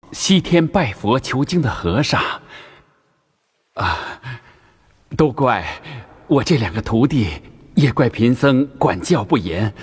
本格的な唐三蔵 AI ボイスオーバー
ゲーム、パロディ、オーディオブックのために、伝説の三蔵法師の穏やかで賢明で独特なトーンで没入感のあるオーディオを作成します。
テキスト読み上げ
僧侶のナレーション
古典的なトーン